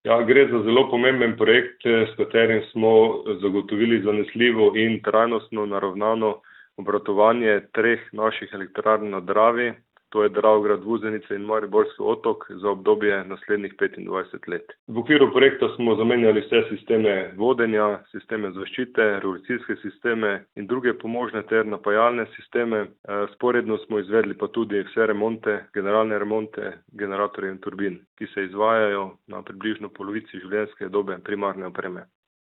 izjava DEM.mp3